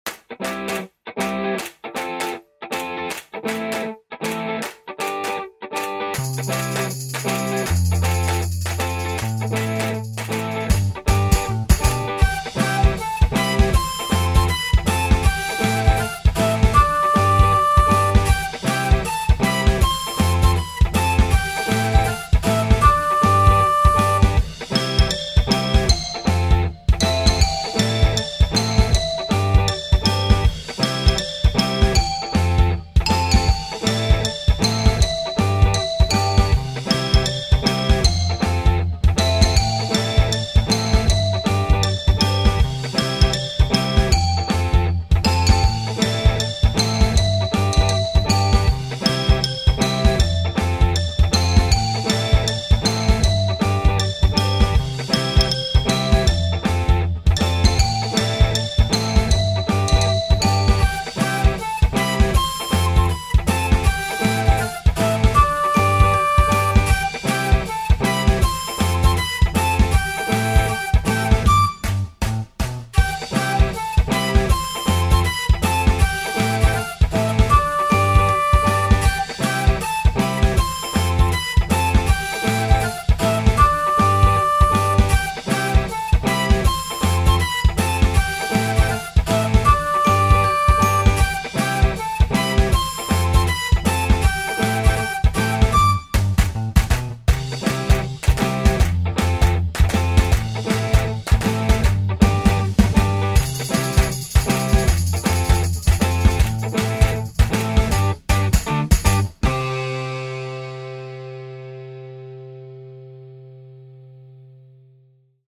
フリーBGM
楽しい わくわく